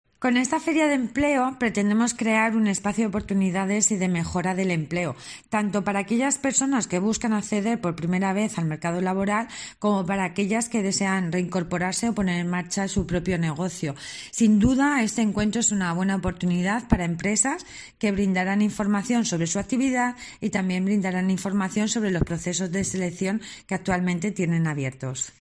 Valle Miguélez, consejera de Empleo